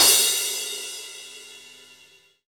Track 14 - Cymbal Crash OS.wav